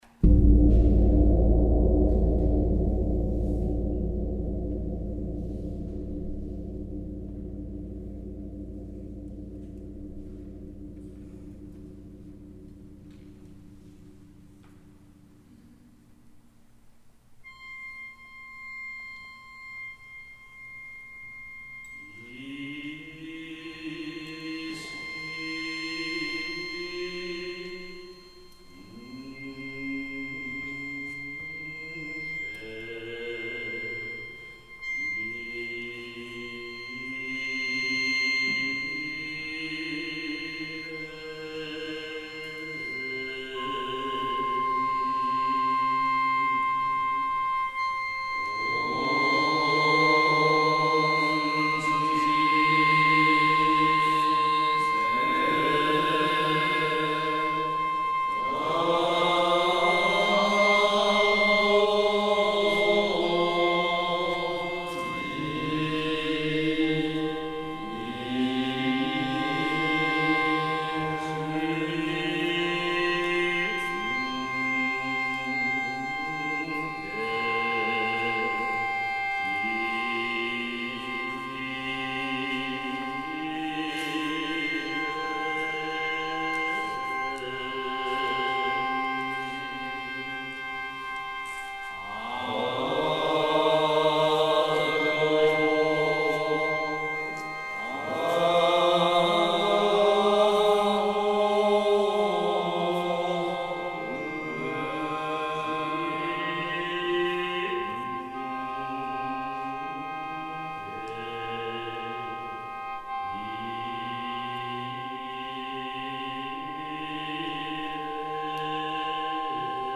Mystical / World
Saxophone, Sheng
Hang, Taiko
25-string Koto, Vocals
Gong, singing Bowl
Shakuhachi